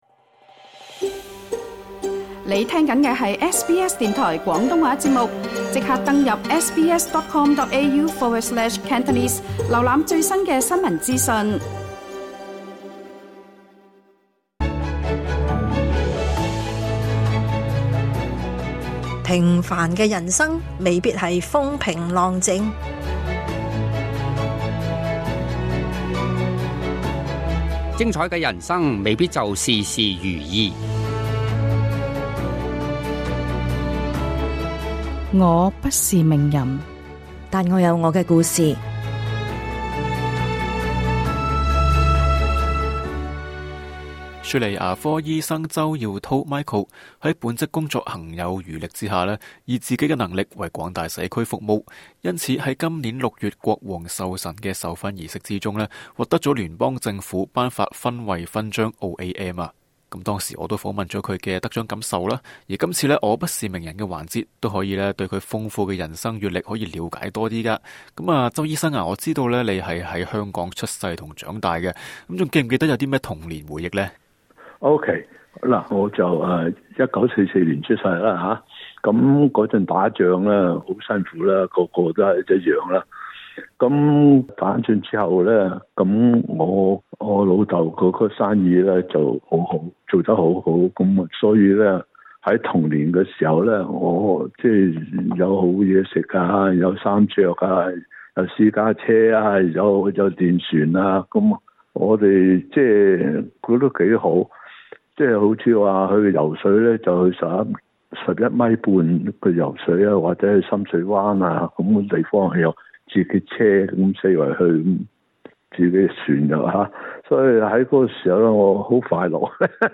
在這集【我不是名人】訪問，他還分享自己在香港出生的童年回憶，以及輾轉澳洲和加拿大求學的經歷——究竟人生是否如他自己所言「咁夠運」呢？